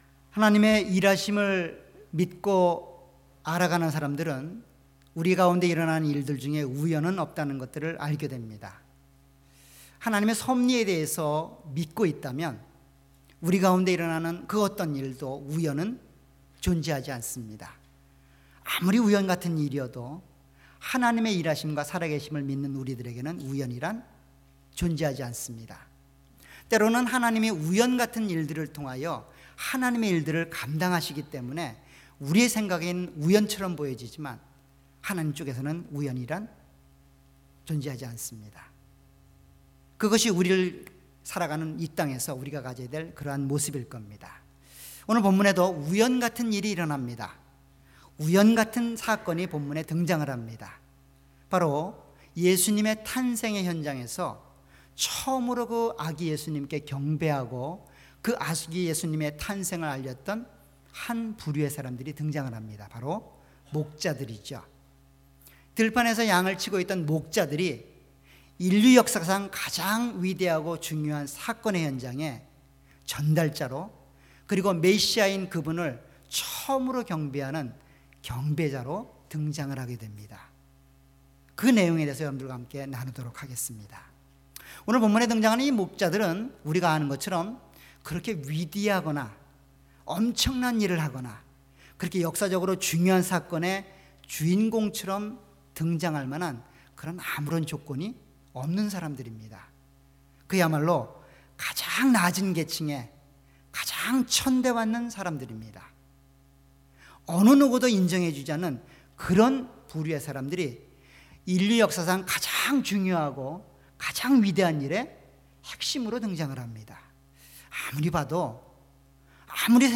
All Sermons
주일예배.Sunday